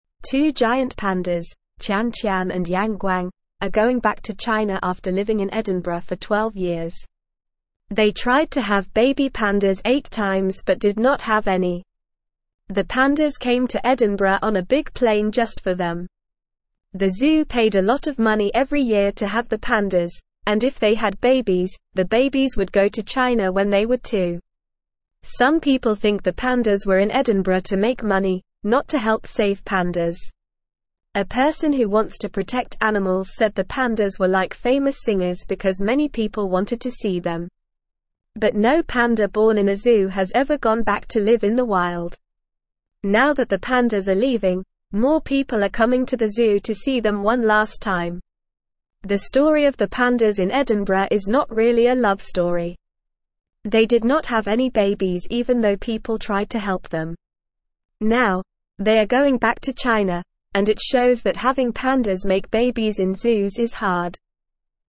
Normal
English-Newsroom-Lower-Intermediate-NORMAL-Reading-Big-Bears-Tian-Tian-and-Yang-Guang-Go-Home.mp3